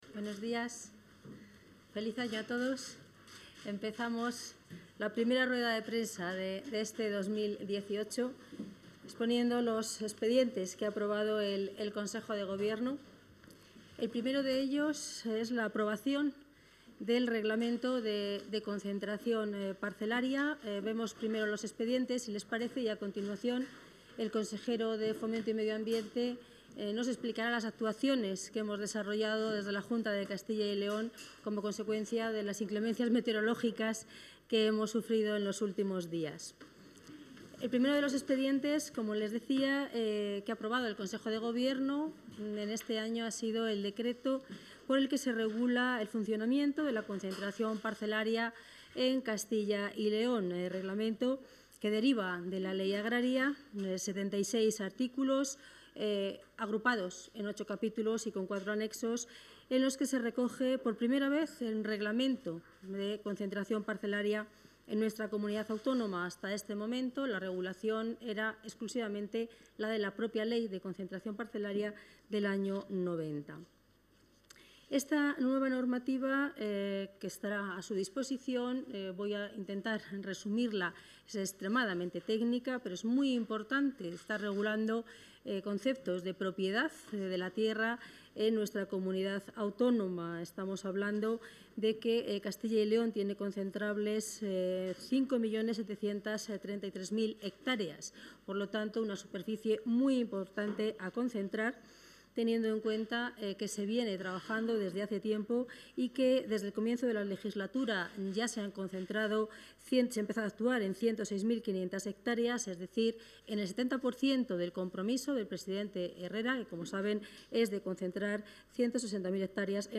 Audio rueda de prensa tras el Consejo de Gobierno.